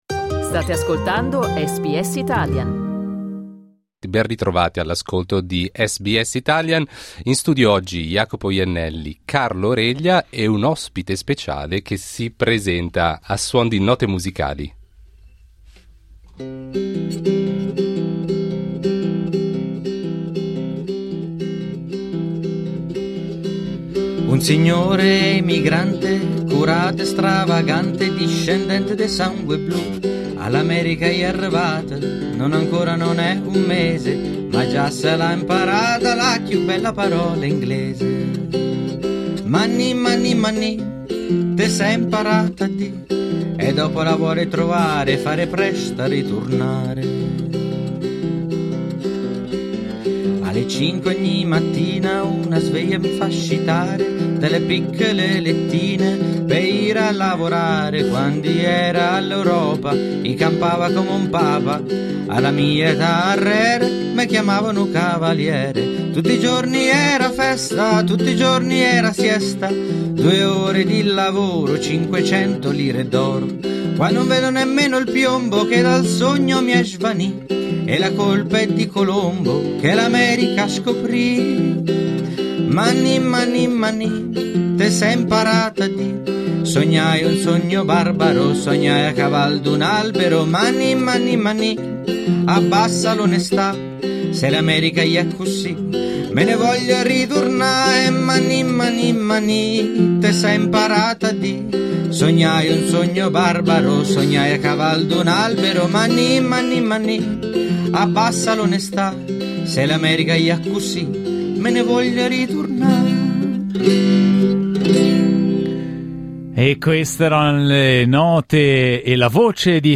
Negli studi di Melbourne di SBS